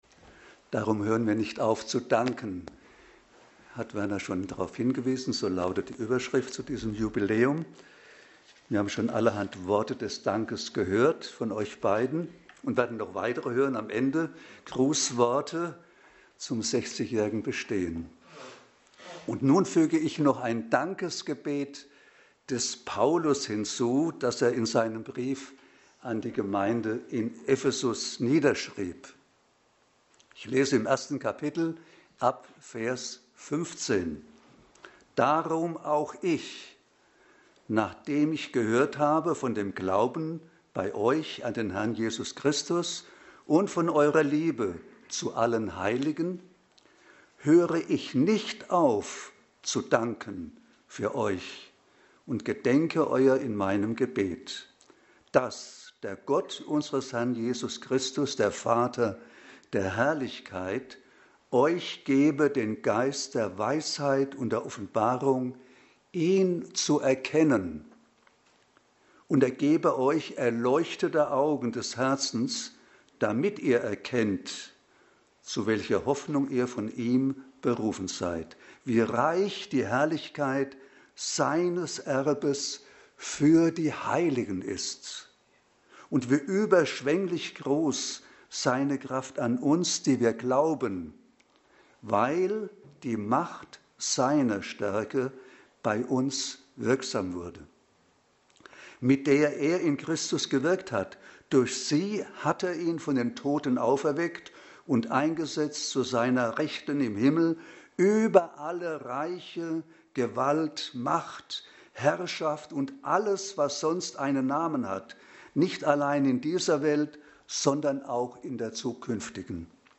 Sonstige Passage: Epheser 1,15-23 Dienstart: Predigt Themen